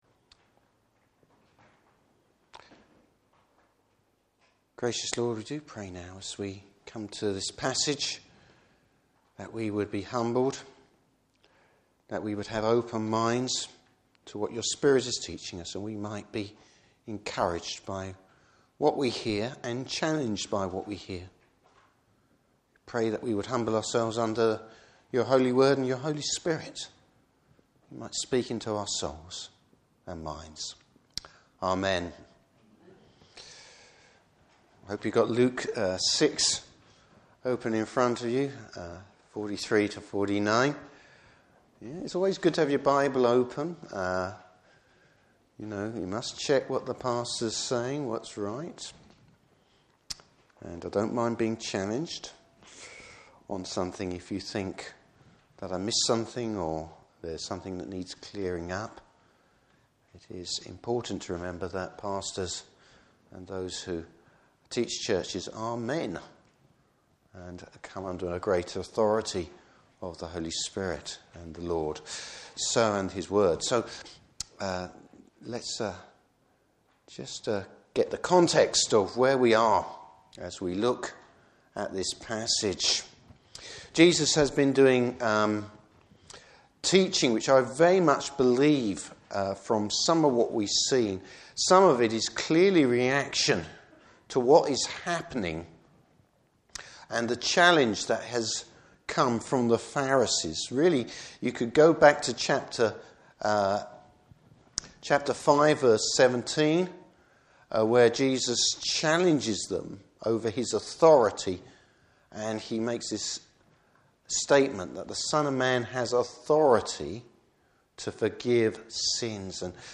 Service Type: Morning Service Bible Text: Luke 6:43-49.